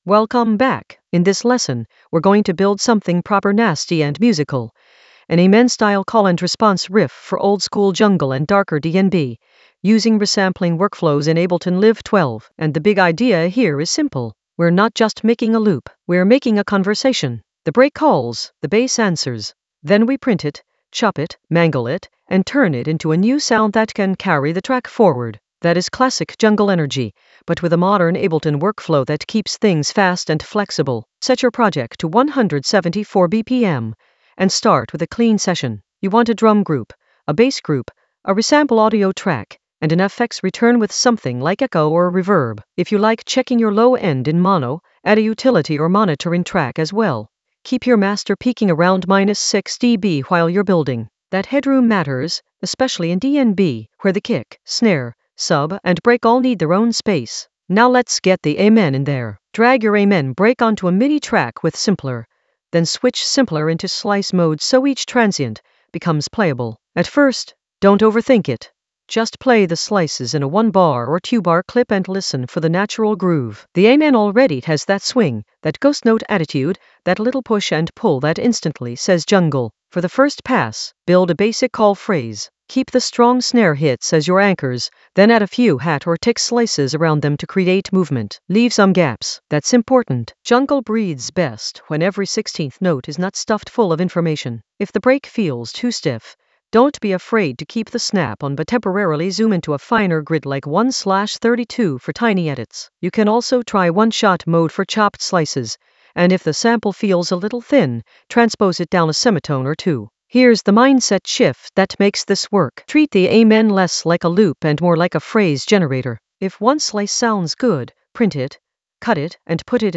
An AI-generated intermediate Ableton lesson focused on Compose an Amen-style call-and-response riff with resampling workflows in Ableton Live 12 for jungle oldskool DnB vibes in the Sound Design area of drum and bass production.
Narrated lesson audio
The voice track includes the tutorial plus extra teacher commentary.